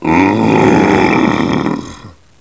assets/psp/nzportable/nzp/sounds/zombie/w8.wav at 9ea766f1c2ff1baf68fe27859b7e5b52b329afea